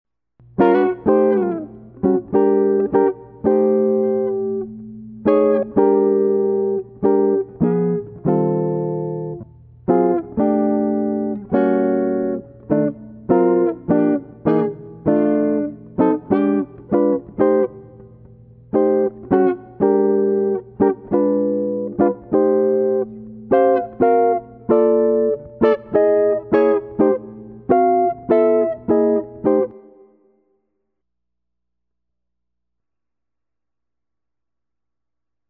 Comping Styles, Chords & Grooves